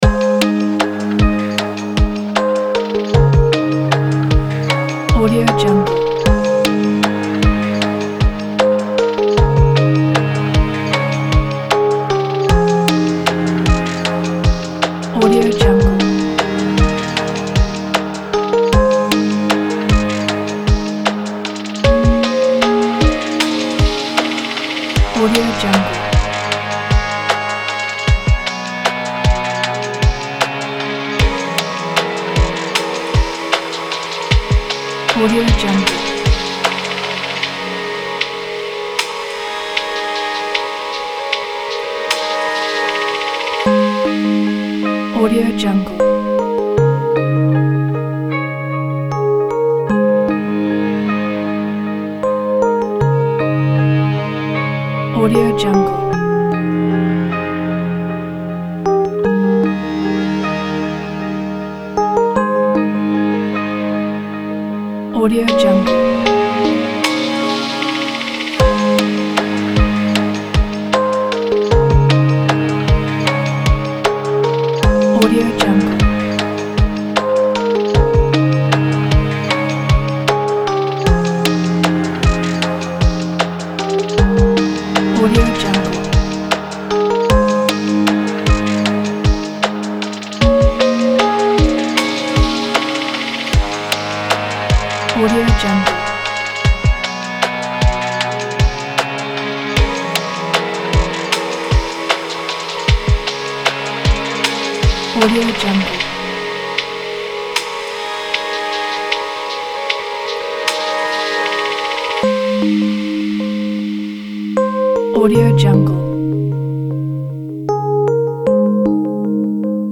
سینمایی